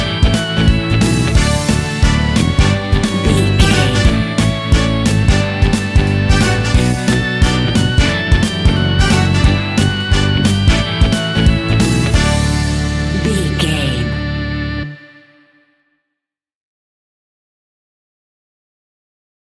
Fast paced
Uplifting
Ionian/Major
D
pop rock
indie pop
fun
energetic
acoustic guitars
drums
bass guitar
electric guitar
piano
organ